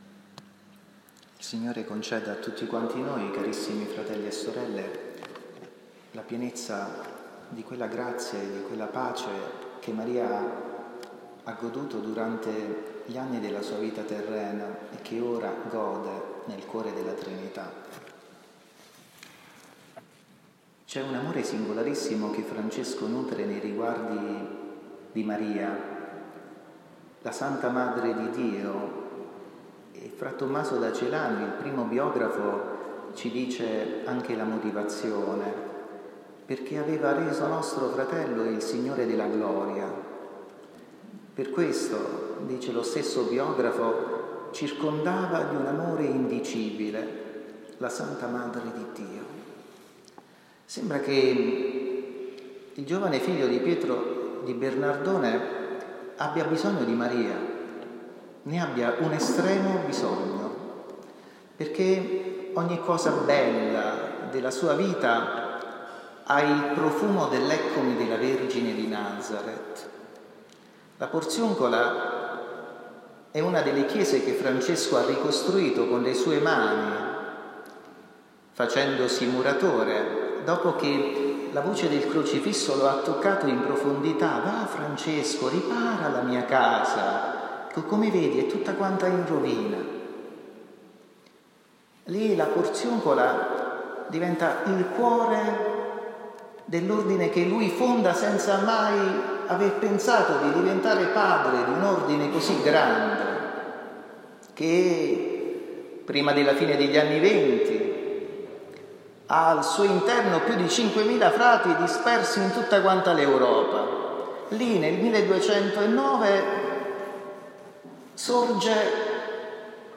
Omelia audio.
SOLENNITÀ DI SANTA MARIA DEGLI ANGELI – 2 agosto 2023